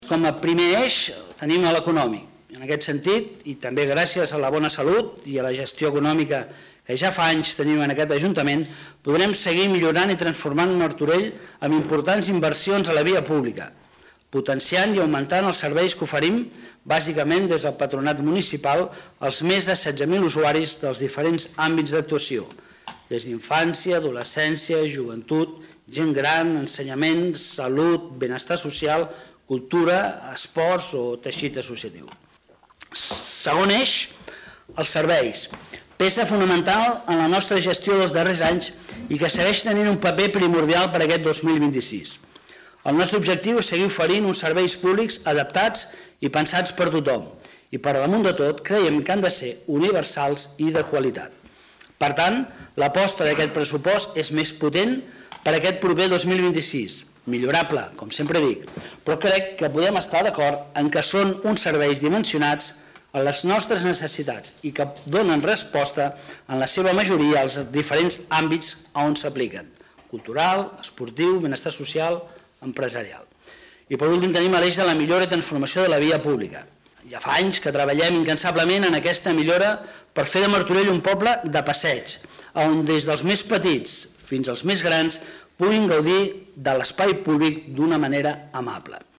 Lluís Amat, regidor d'Hisenda i Règim Interior
Ple-Municipal-Desembre-01.-Lluis-Amat.mp3